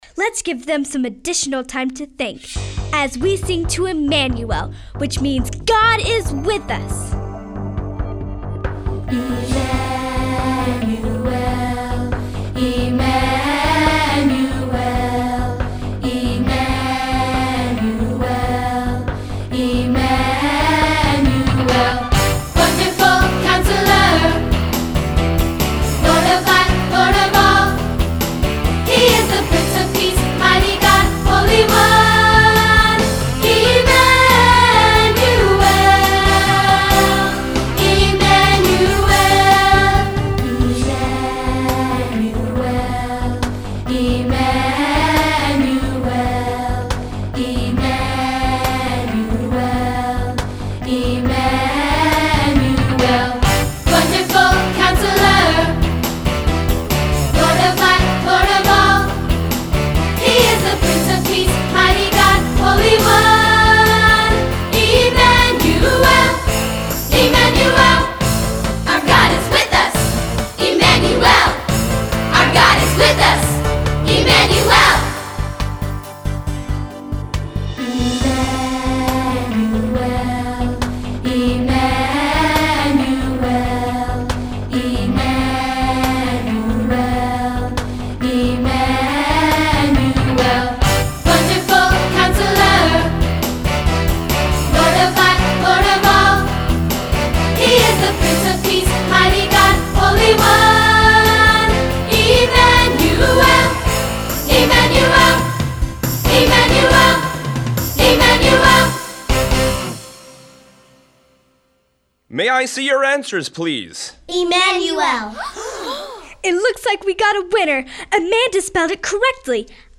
Sunday School Christmas Program | Bethel Lutheran Church
EmmanuelDemoforBethelYouth.mp3